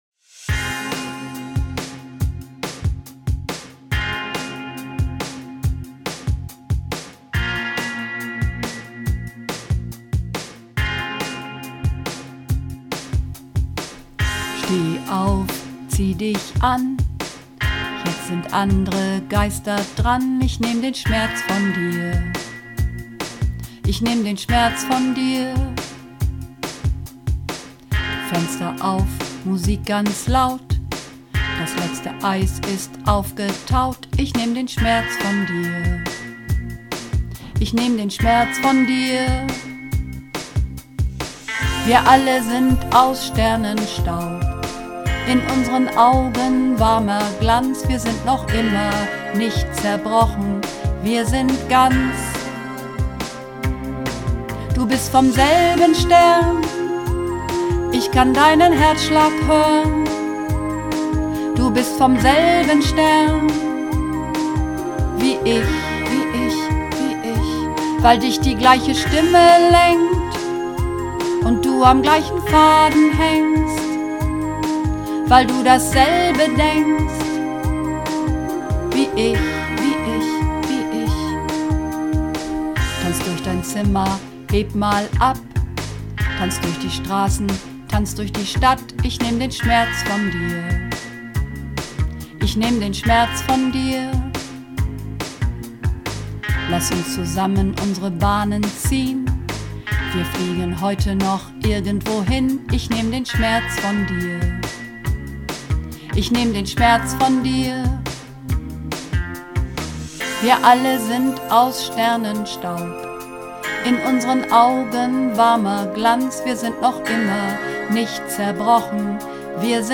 Übungsaufnahmen - Vom selben Stern
Vom selben Stern (Alt und Bass)